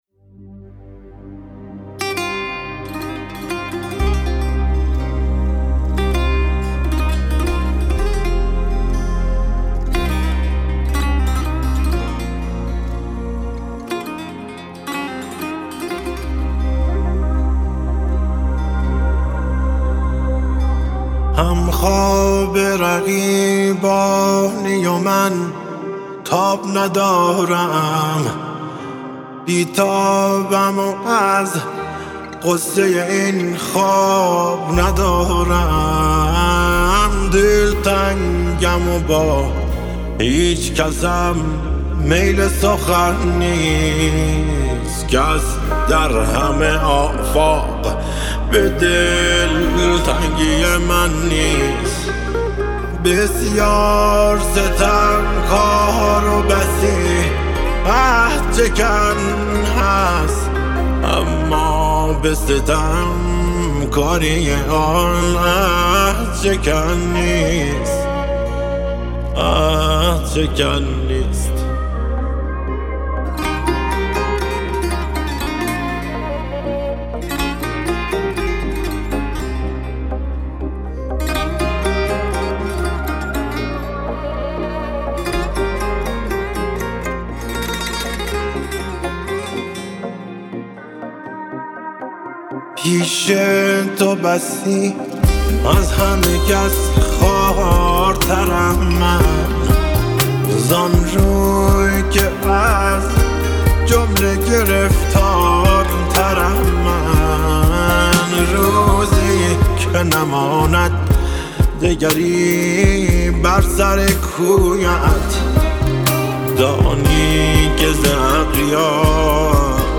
با صدای دلنشین
ملودی احساسی